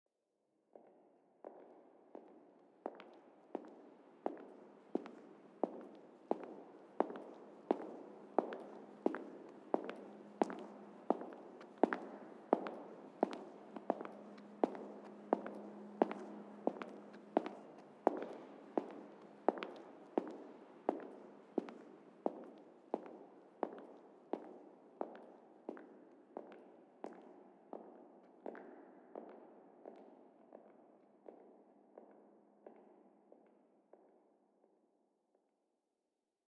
Compositional excerpts were created using a mixture of recognizable, real-world field recordings, processed recordings and synthetic pitched materials. Several excerpts exhibit phonographic approaches, while others involve sound-image transformations (i.e. sonic transmutation between two recognizable sounds) or interplay between synthetic pitched materials and concrète materials. The excerpts contained some repeating sounds — for instance, the sound of footsteps — while other sounds appeared only once.